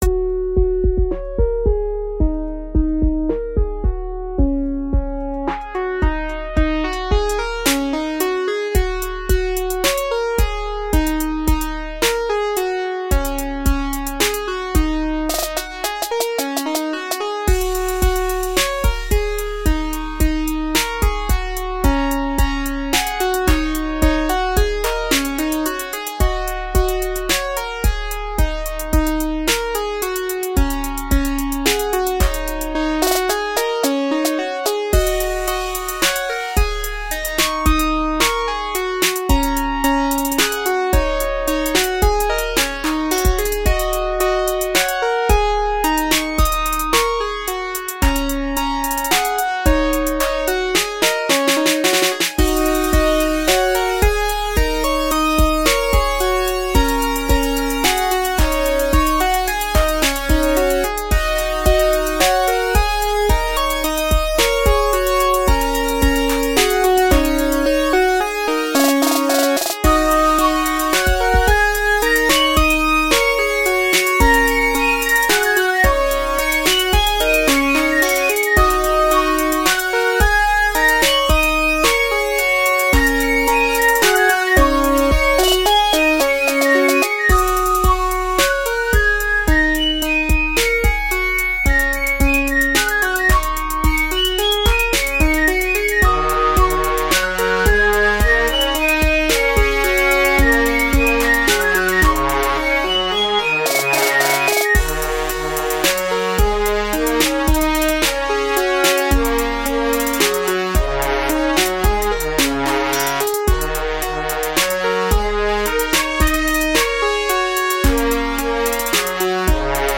An experiment with a chinese style of music.
:) Note: It sounds like the song has ended about halfway through, but it's just a short period of silence.